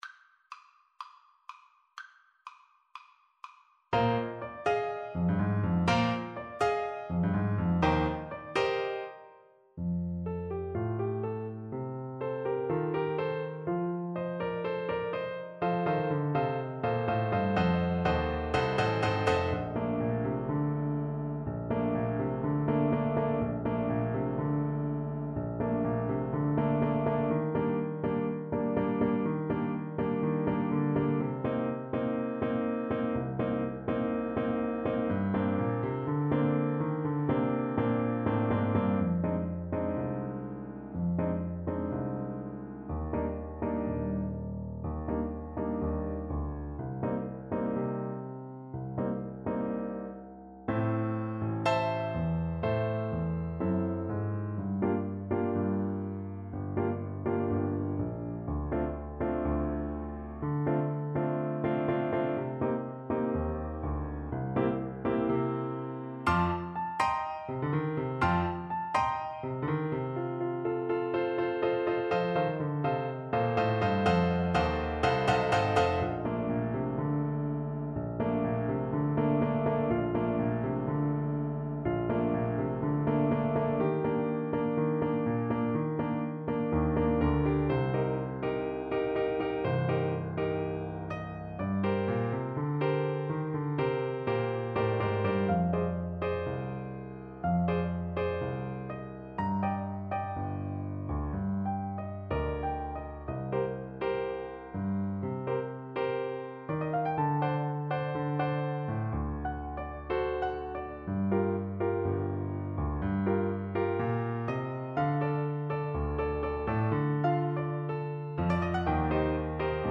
= c. 80 Muy Rapido! = c. 80
2/2 (View more 2/2 Music)
Clarinet  (View more Intermediate Clarinet Music)
Jazz (View more Jazz Clarinet Music)